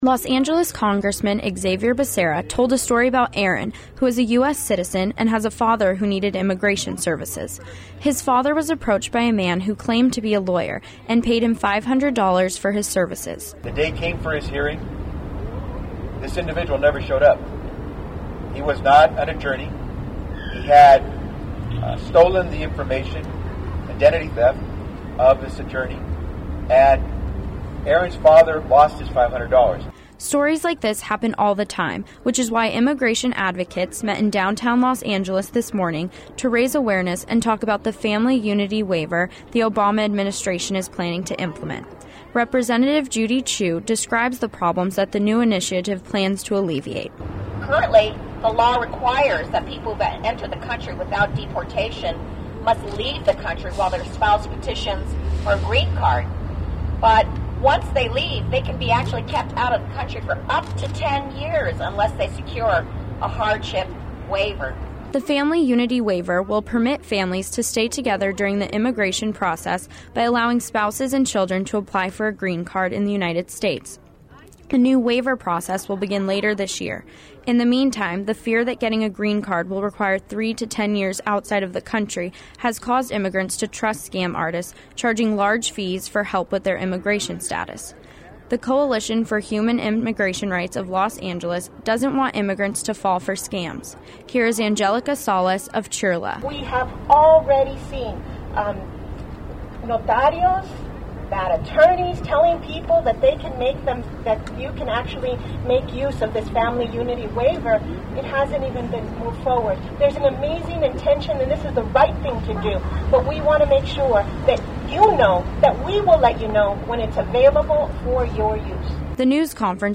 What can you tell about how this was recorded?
The news conference drew a crowd of people asking how to get their own help, proving that as more knowledge and resources are made available, the more immigrants and families can be helped.